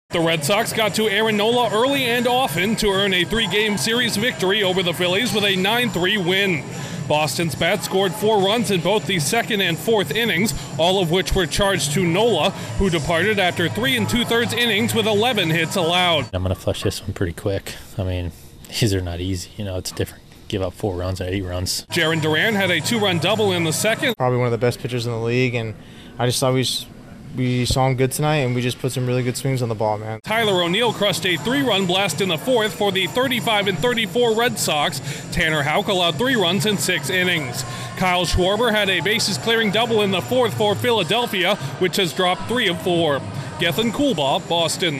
The Red Sox rough up one of the best pitchers in baseball on the way to a surprising series victory. Correspondent